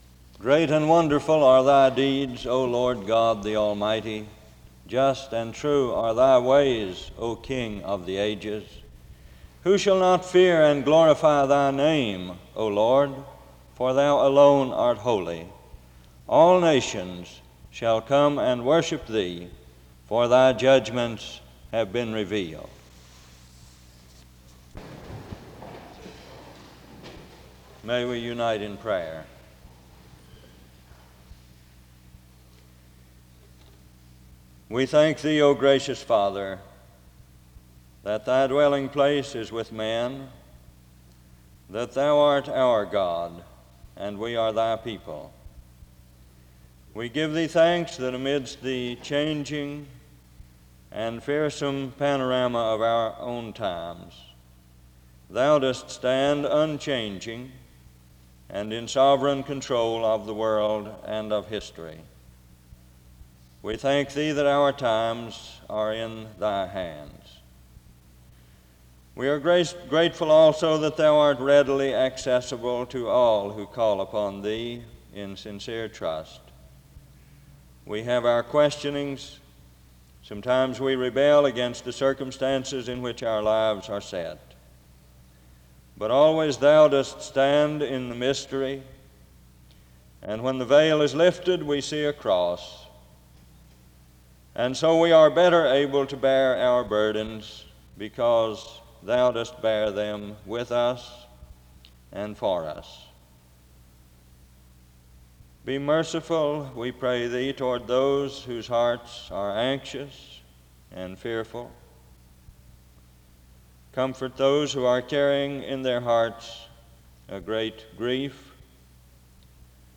The service begins with a scripture reading form 0:00-0:23. A prayer is offered from 0:28-2:42.
An introduction to the speaker is given from 2:49-3:48.
SEBTS Chapel and Special Event Recordings SEBTS Chapel and Special Event Recordings